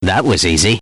that-was-easy-sound.mp3